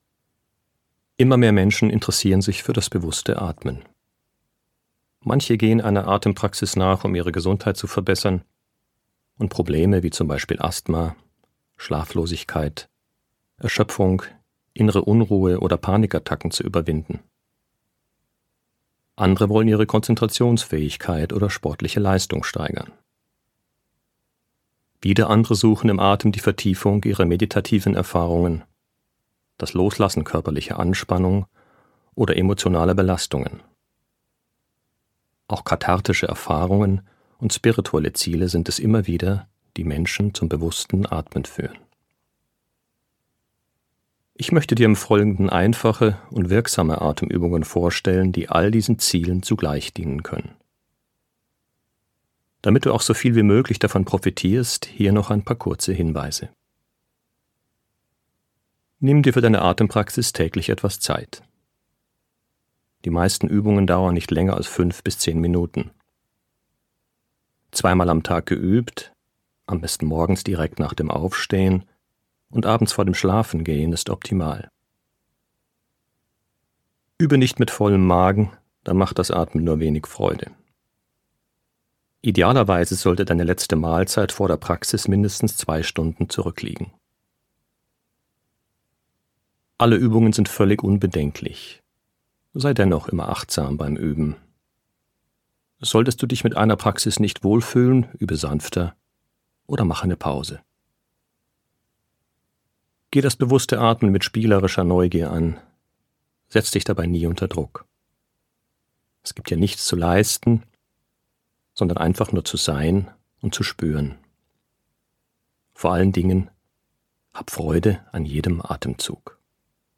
2022 | 2. Auflage, Ungekürzte Ausgabe
Geführte Übungen für Ihre tägliche Atempraxis